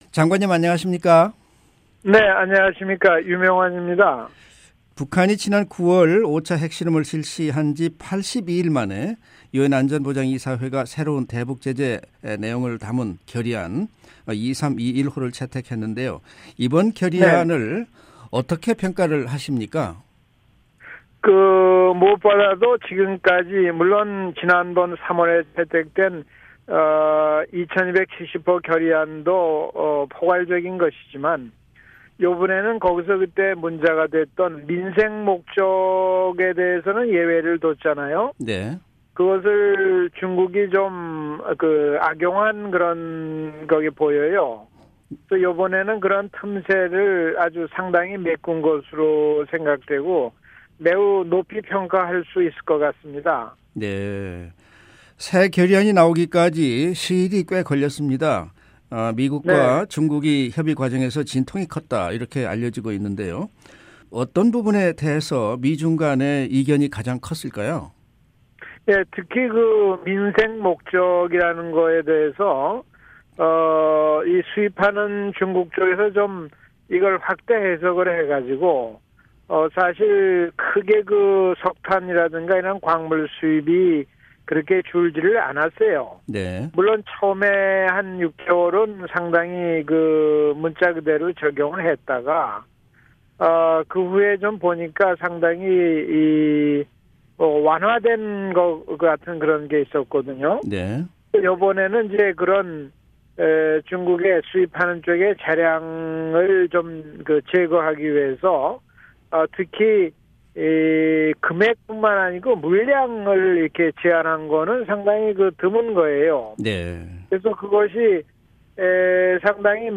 [인터뷰: 유명환 전 한국 외교장관] 유엔 안보리 새 대북제재결의 채택…의미와 전망
[인터뷰 오디오: 유명환 전 한국 외교장관] 유엔 안보리 대북제재 새 결의안 채택…의미와 전망